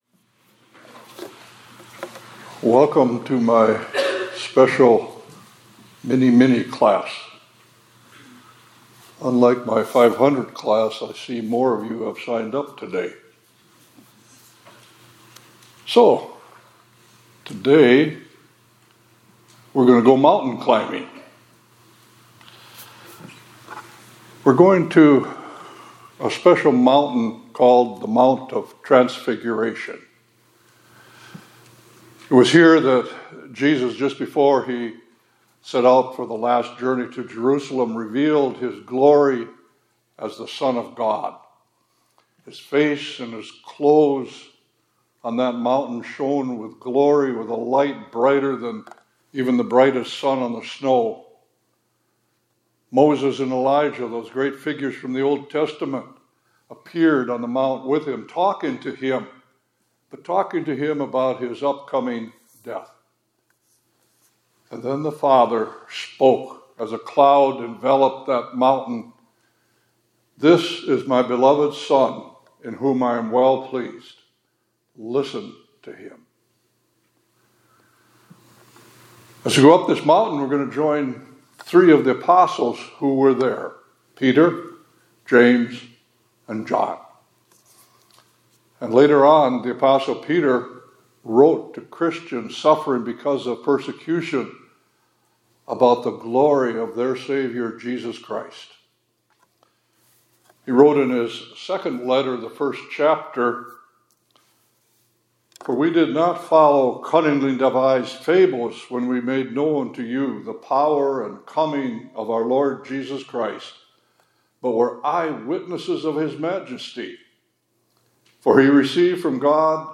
2025-02-20 ILC Chapel — We Have a More Sure Word of Prophecy